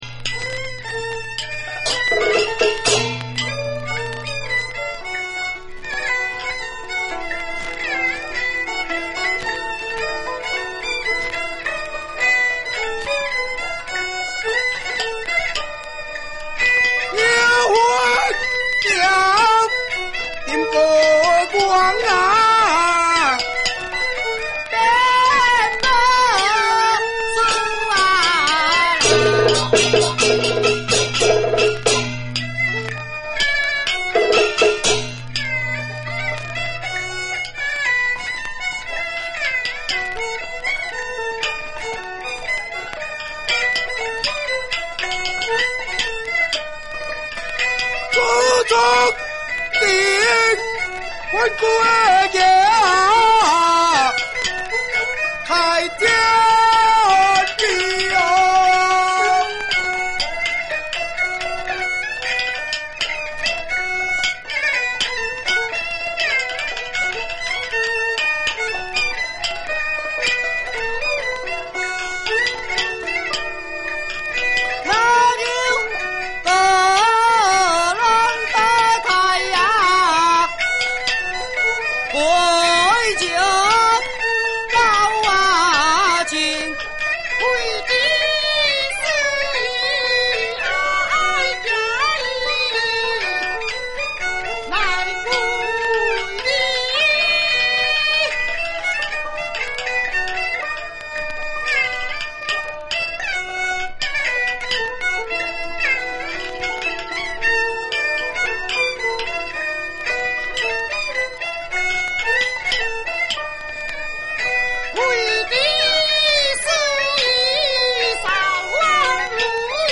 三進宮【西皮】 北管新路戲曲 梨春園早期錄音資料計畫